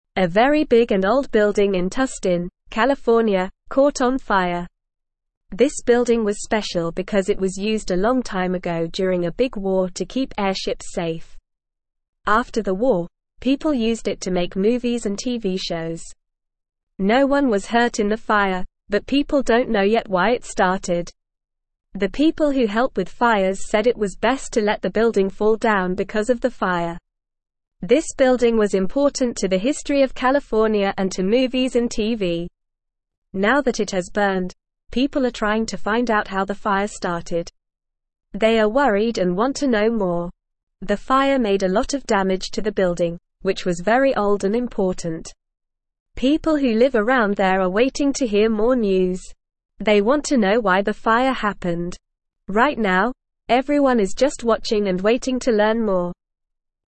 Normal
English-Newsroom-Lower-Intermediate-NORMAL-Reading-Big-Old-Building-in-California-Catches-Fire.mp3